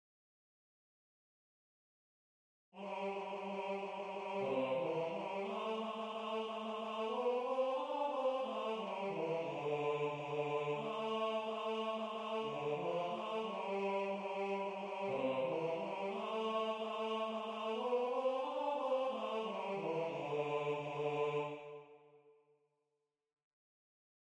MP3 rendu voix synth.
Tenor 1